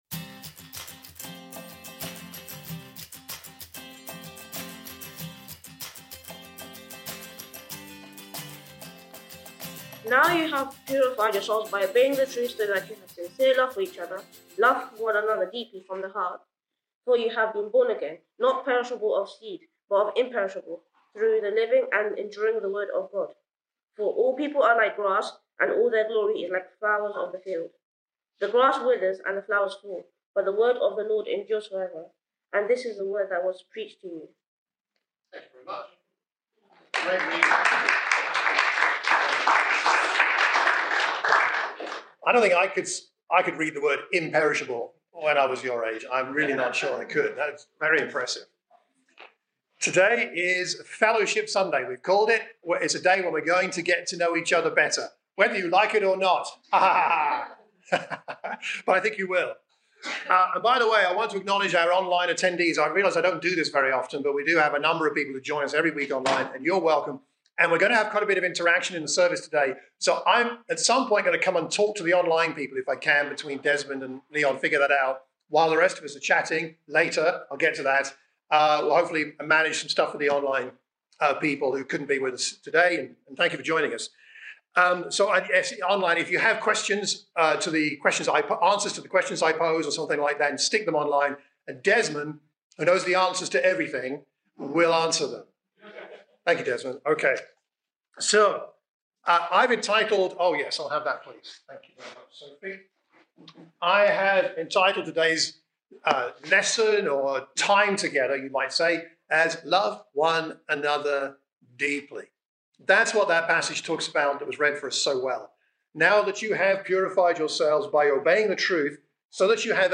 In this interactive lesson, we explored how we deepen our fellowship in a way modelled after the way Christ loved his disciples (John 13:34-35).